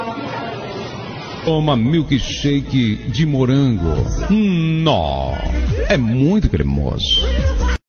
toma milk shake de morango Meme Sound Effect
This sound is perfect for adding humor, surprise, or dramatic timing to your content.